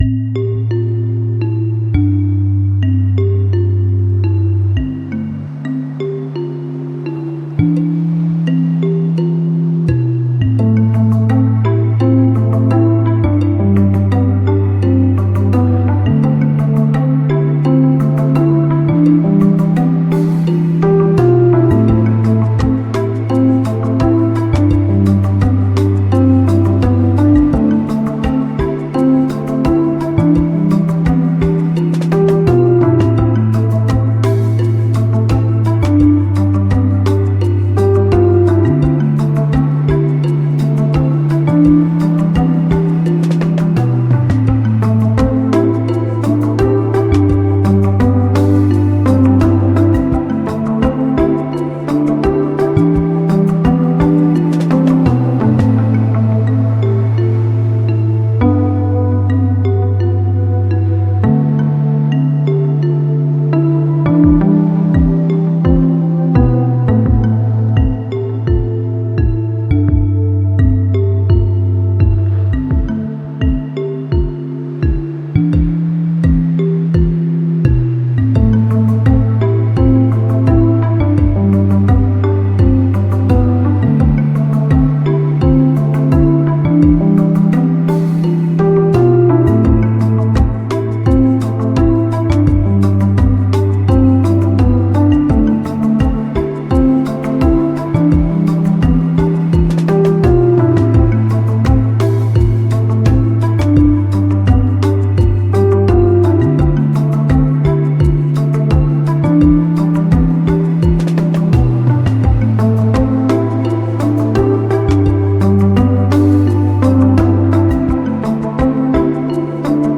lo-fi beats